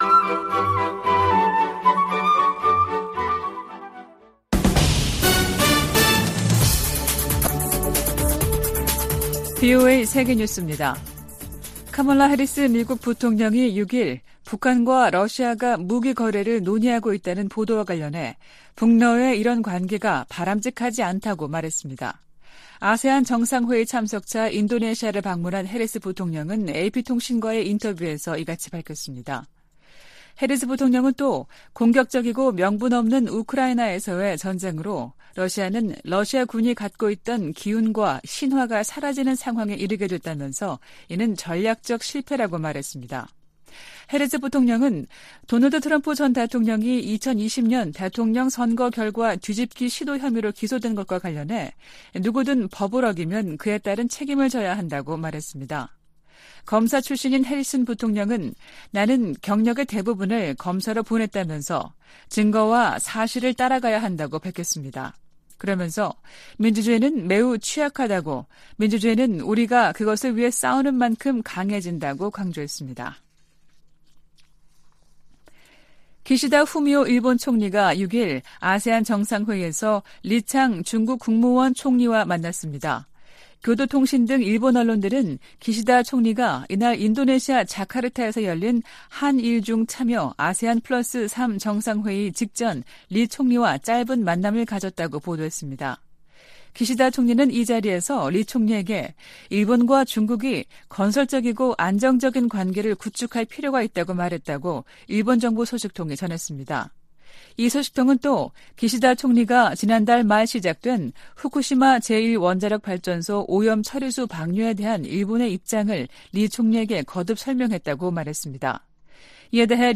VOA 한국어 아침 뉴스 프로그램 '워싱턴 뉴스 광장' 2023년 9월 7일 방송입니다. 백악관은 북한과 러시아가 정상 회담을 추진하고 있다는 보도가 나온 가운데 양국 간 무기 협상을 중단하라고 촉구했습니다. 윤석열 한국 대통령도 북-러 군사협력을 시도하지 말라고 요구했습니다.